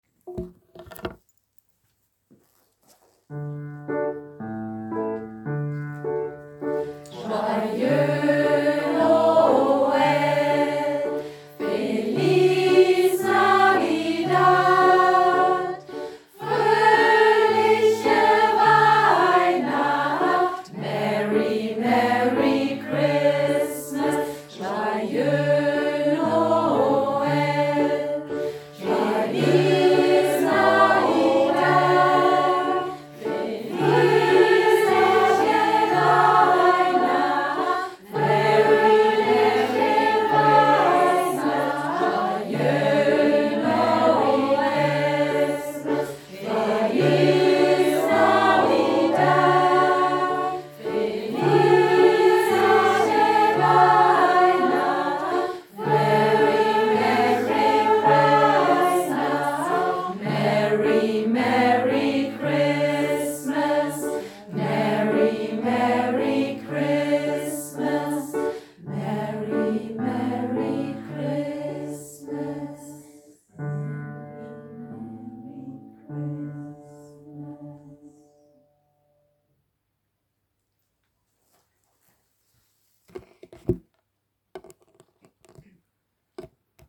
Joyeux Noël, Feliz Navidad, Fröhliche Weihnacht und Merry Christmas verbinden sich zu einem eingängigen,  stimmungsvollen Kanon.
Weihnachtsgrüße aus aller Welt - zum Hineinhören (hier in D-Dur mit Lehrkräften gesungen, mit Kindern besser in F-Dur wie im Liedblatt)
Weihnachtsgruesse-kanon-BLKM_D-Dur.mp3